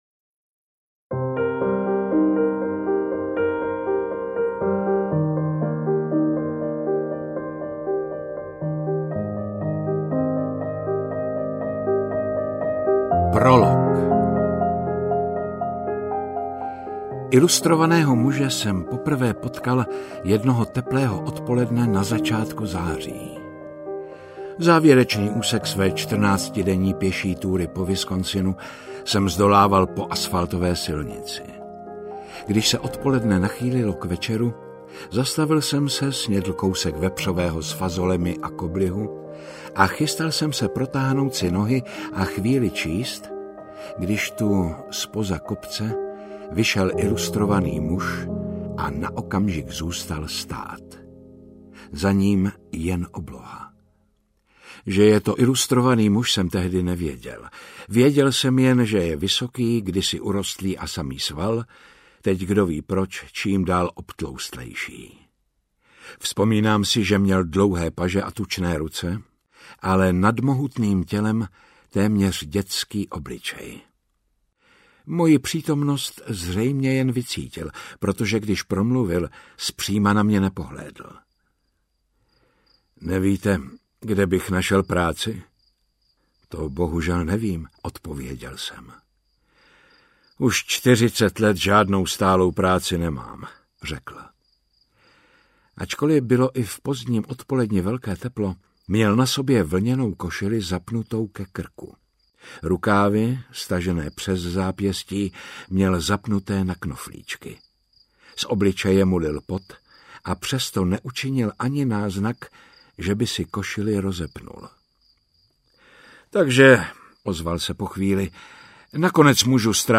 Interpret:  Jaromír Meduna
AudioKniha ke stažení, 20 x mp3, délka 10 hod. 24 min., velikost 570,4 MB, česky